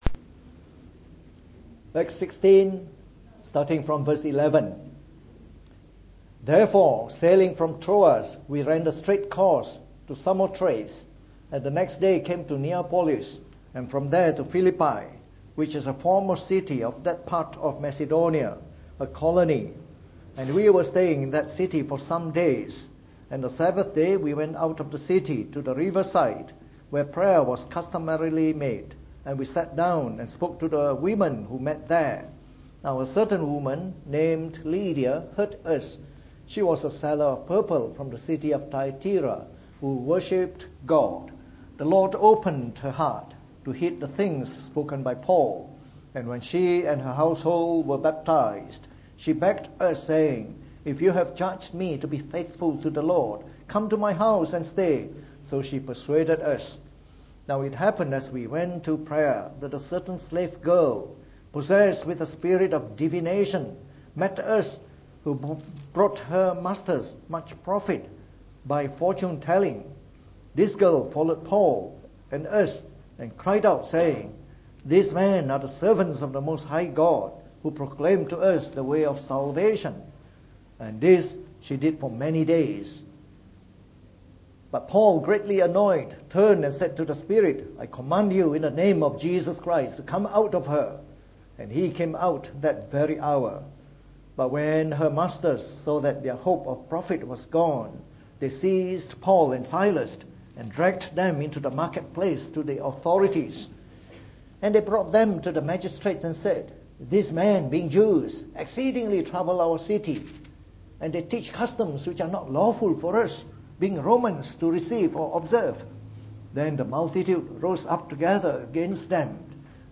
From our new series on the Epistle to the Philippians delivered in the Morning Service.